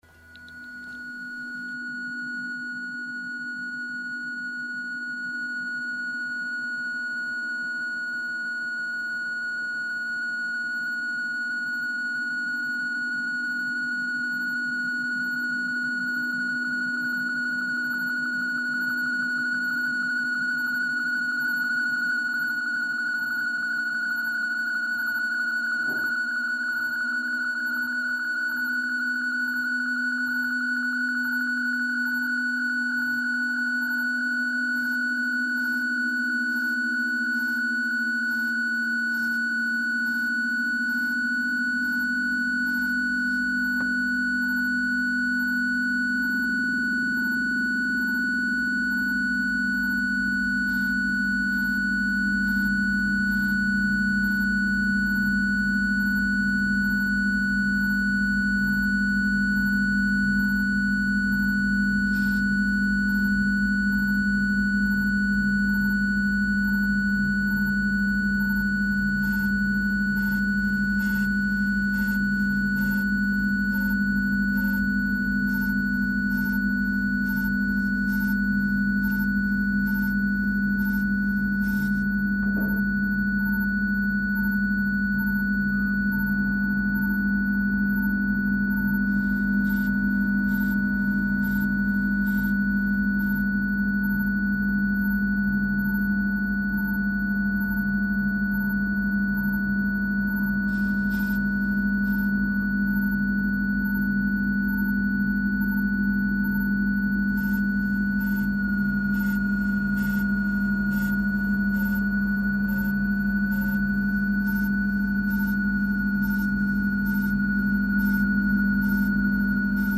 Interview // 21 June 2010